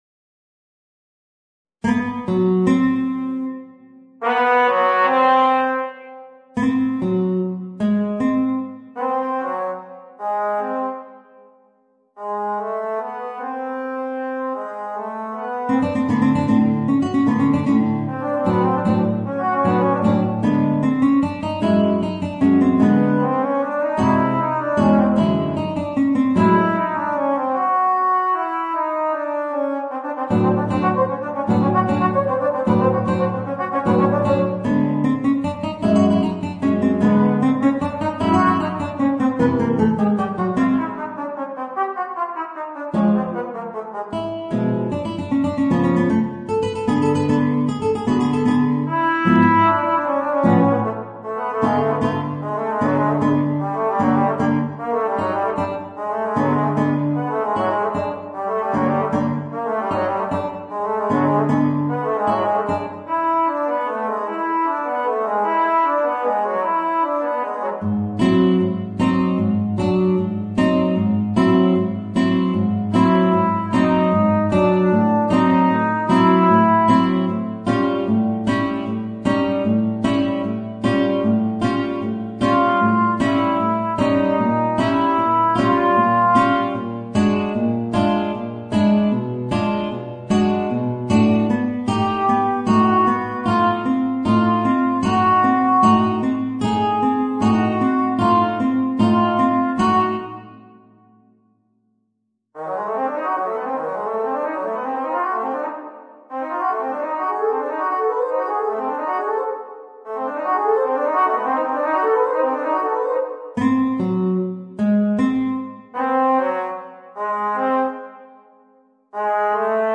Voicing: Guitar and Alto Trombone